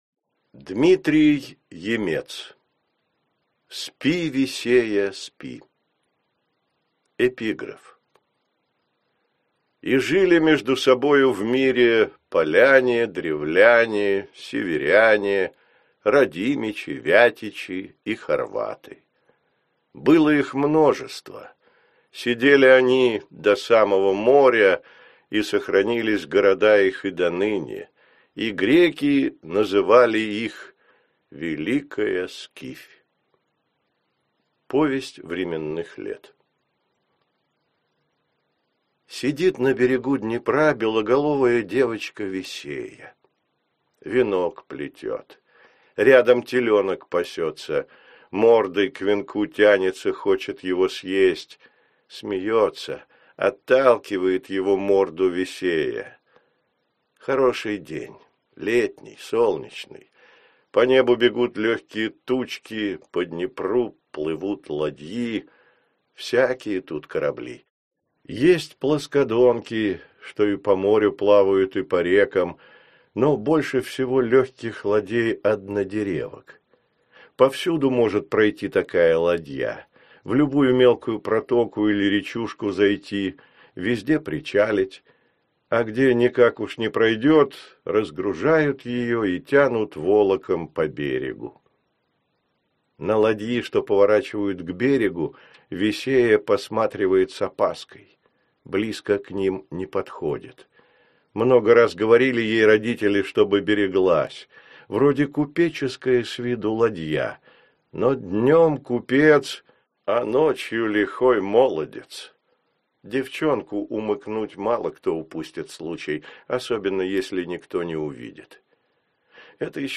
Аудиокнига Древняя Русь. История в рассказах для школьников | Библиотека аудиокниг